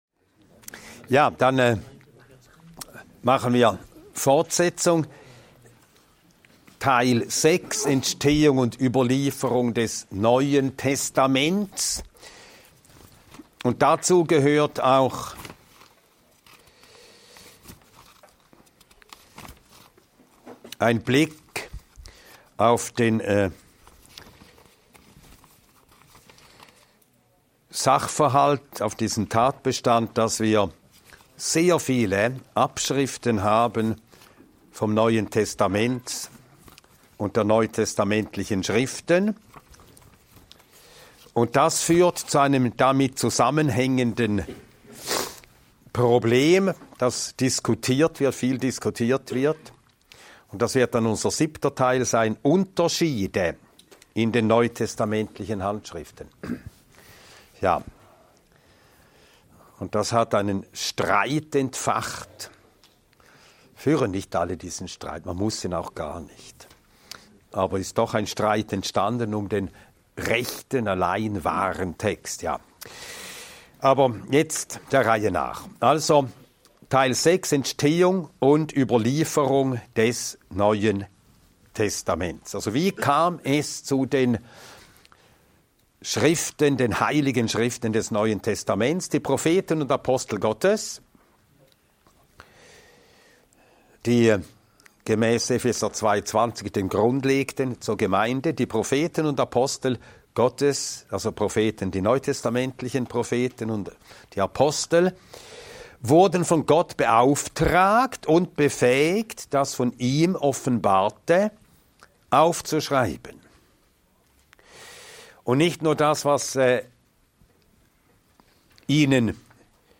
Wie entstand das Neue Testament? Dieser Vortrag beleuchtet die historische Entstehung und Überlieferung der neutestamentlichen Schriften: Von der Inspiration und Autorität der Apostel über die Rolle des Heiligen Geistes bis hin zur allgemeinen Anerkennung der Schriften durch die frühe Gemeinde.